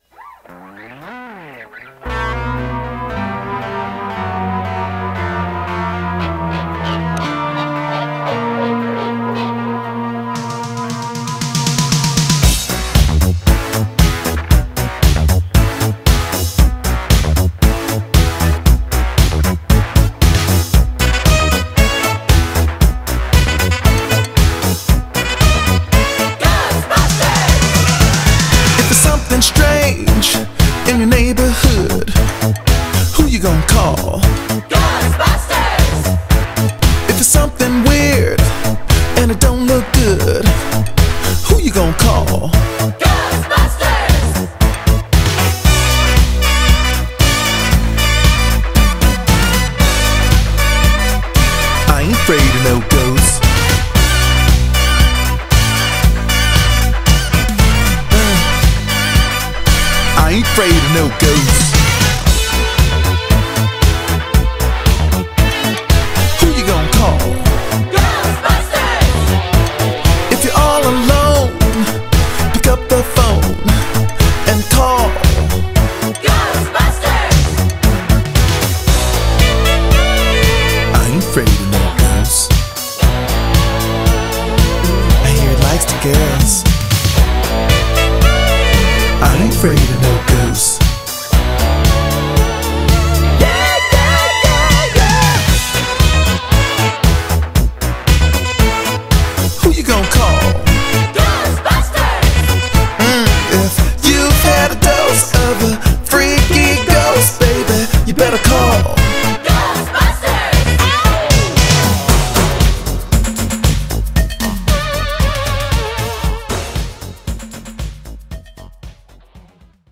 BPM116
Audio QualityMusic Cut